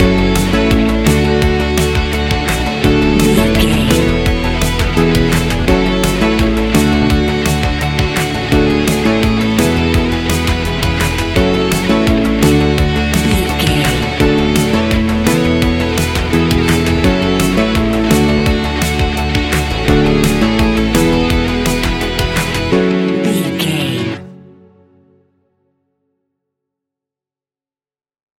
Ionian/Major
ambient
electronic
new age
downtempo
pads